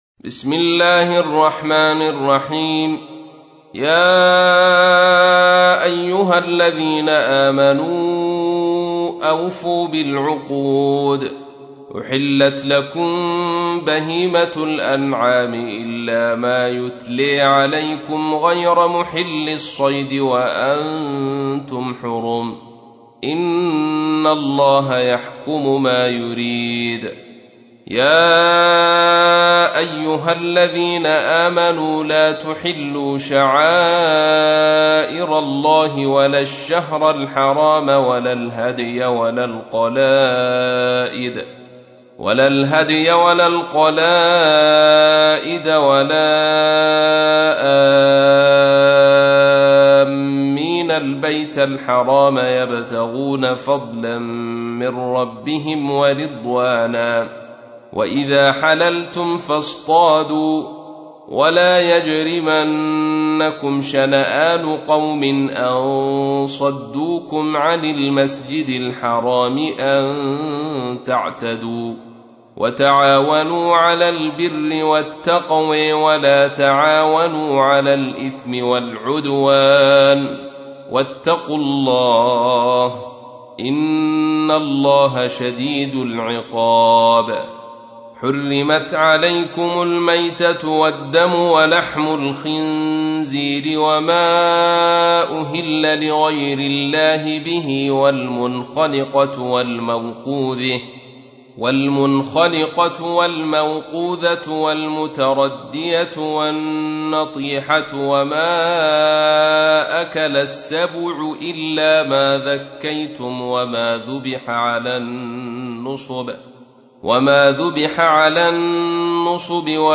تحميل : 5. سورة المائدة / القارئ عبد الرشيد صوفي / القرآن الكريم / موقع يا حسين